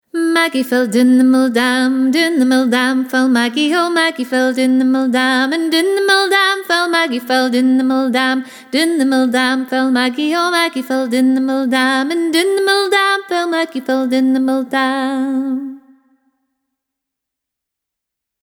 Scottish Music Download Maggie Fell Doon The Mill Dam MP3